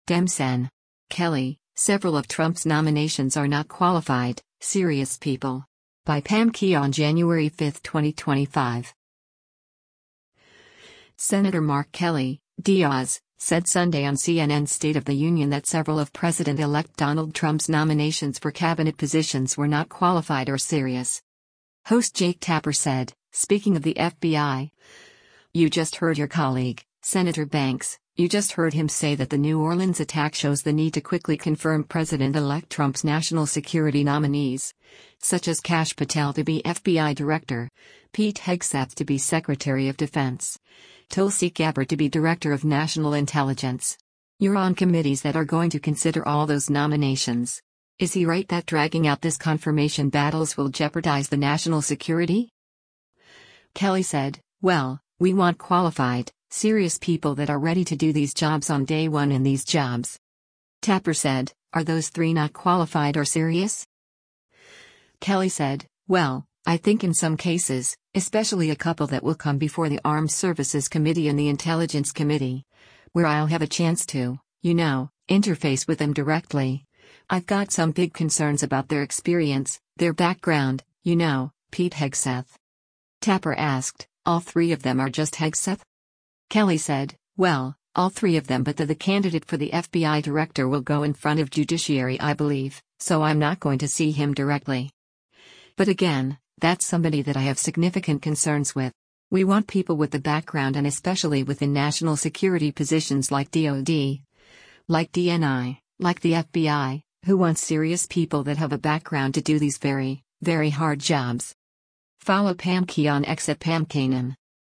Senator Mark Kelly (D-AZ) said Sunday on CNN’s “State of the Union” that several of President-elect Donald Trump’s nominations for cabinet positions were not “qualified or serious.”